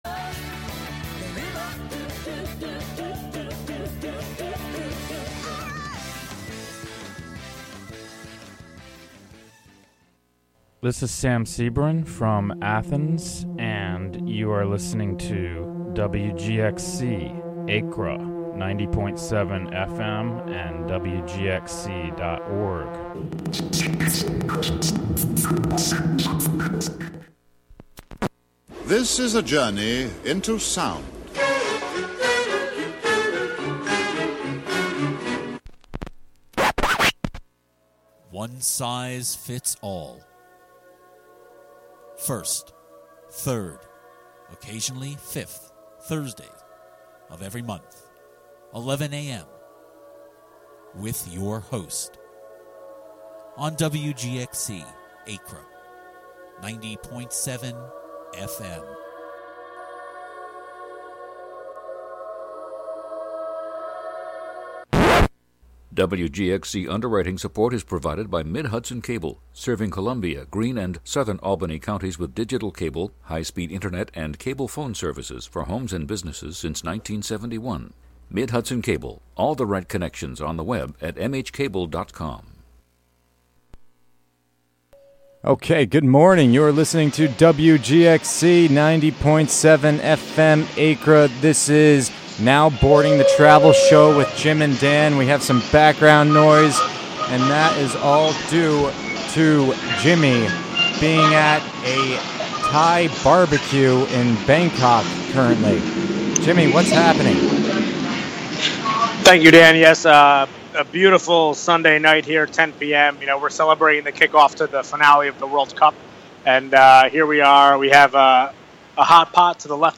Largely focused around subjects of travel, with 2 co-hosts who jab each other over their travel adventures, good or bad, wise and incredibly idiotic, with perceived notions and epiphanies, but always influenced by the redeeming essence of a brave new step to be taken via the way of travel.